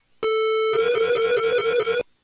描述：带32mb卡和i kimu软件的gameboy样品